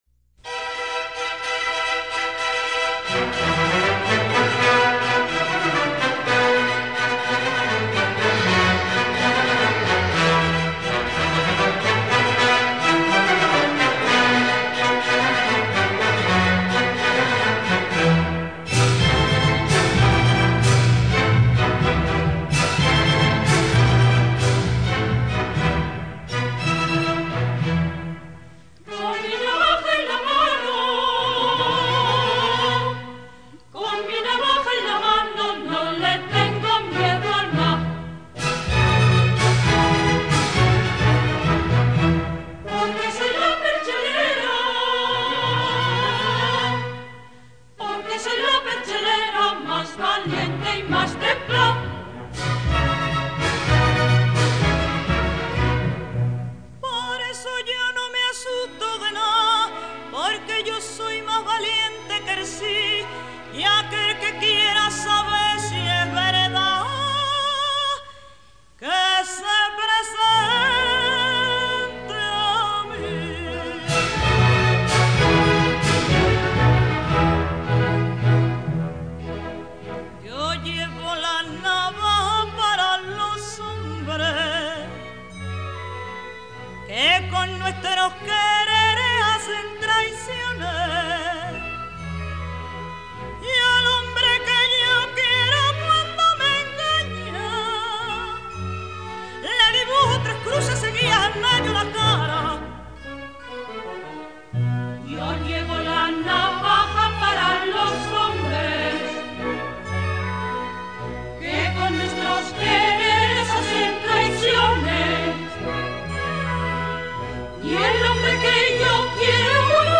Coro y Orquesta de Cámara de Madrid
• El zapateado y Habanera
el cor femení